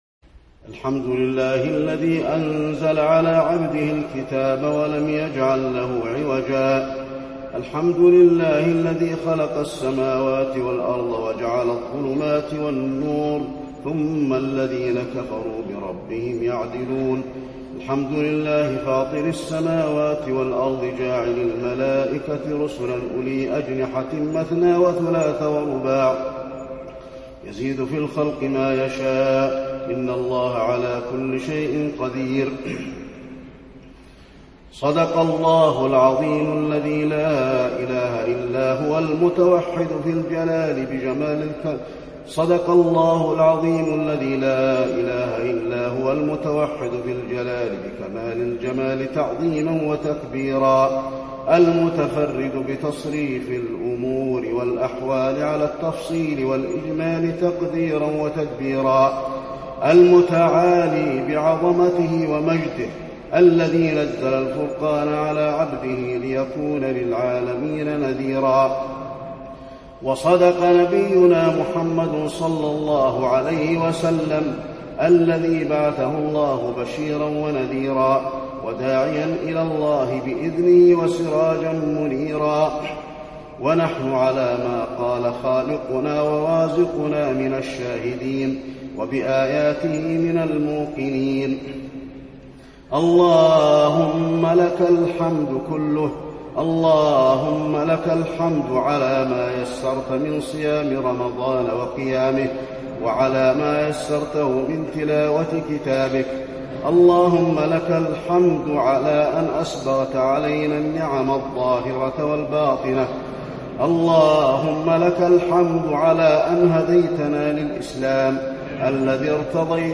المكان: المسجد النبوي ختم القرآن